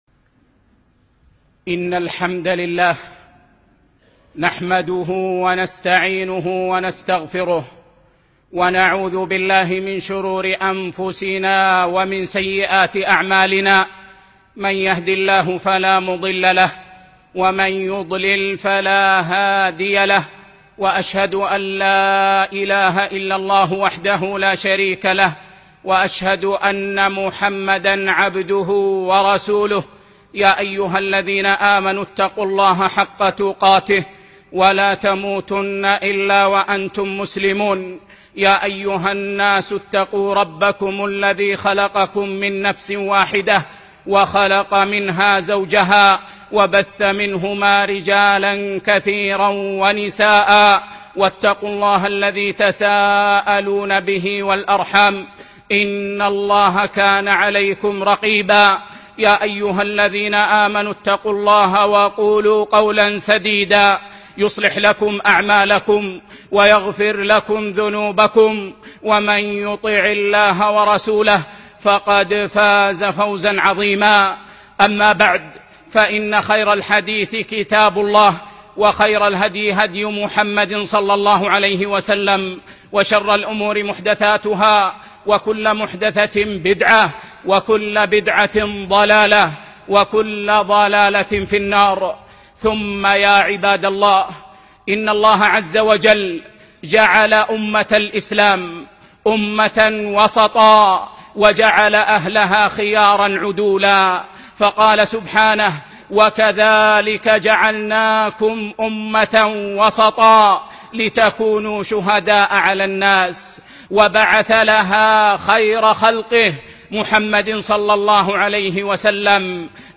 الجمعة 18 شعبان 1436 الموافق 5 6 2015 بمسجد بلال بن رباح رضي الله عنه
تحصين الأبناء من التطرّف - خطبة